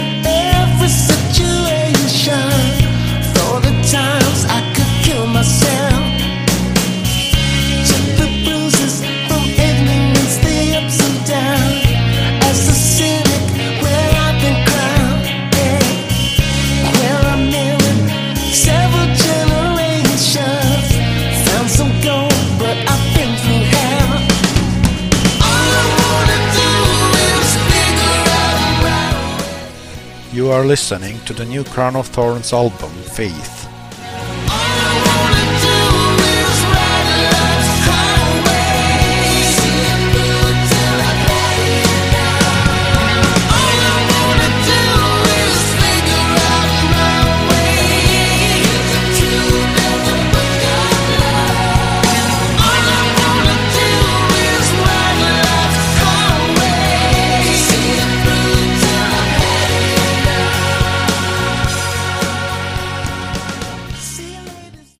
Category: AOR
lead and backing vocals
keyboards
drums, percussion